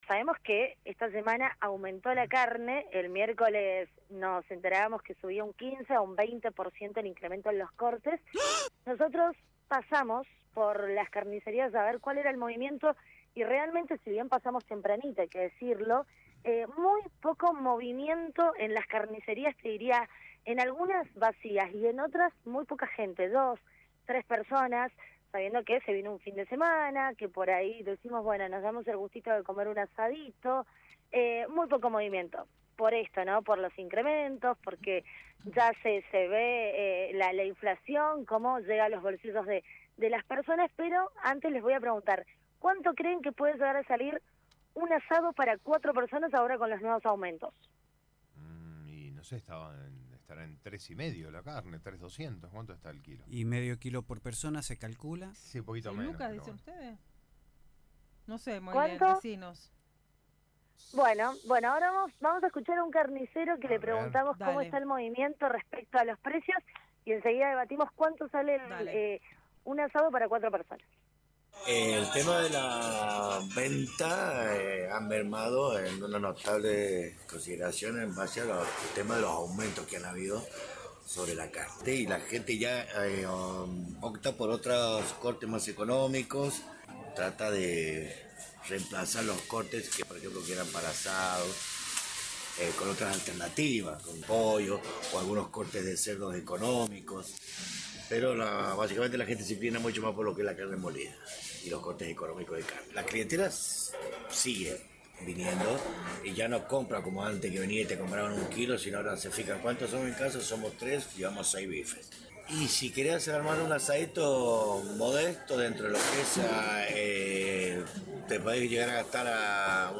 LVDiez - Radio de Cuyo - Móvil de LVDiez- recorrida por carnicerías tras aumento de la carne